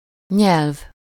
Ääntäminen
IPA : /ˈlæŋɡwɪdʒ/